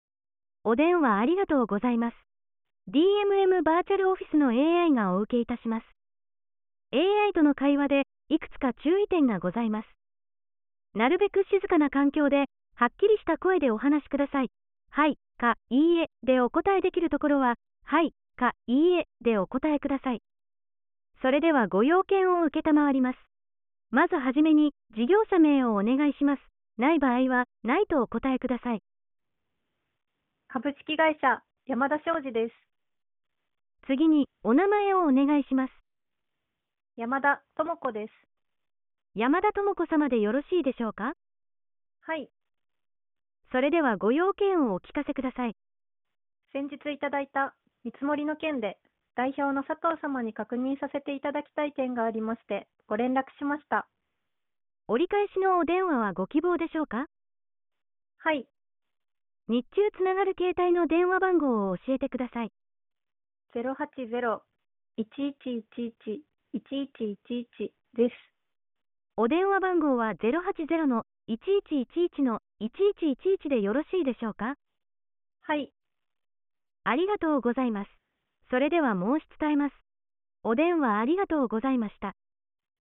あなたの代わりにAIが電話応答してくれる 電話代行サービス
AI通話サンプル